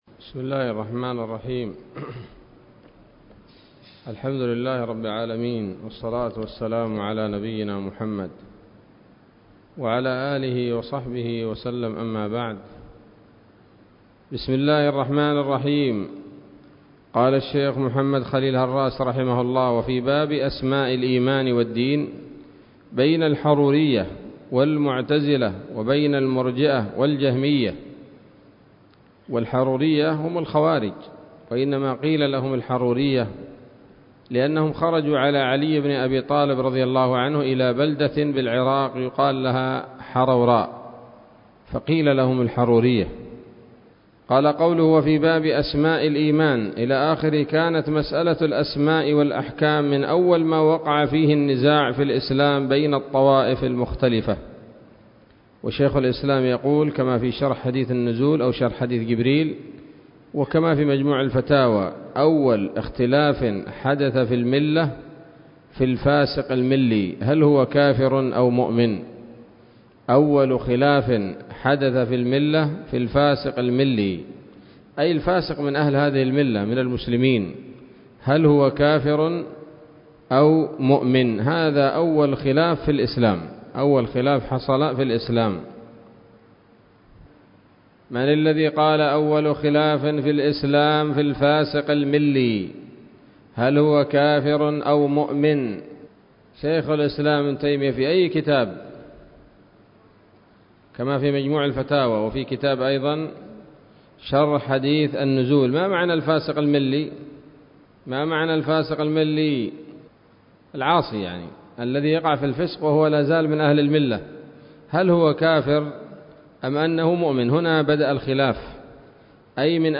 الدرس الحادي والتسعون من شرح العقيدة الواسطية للهراس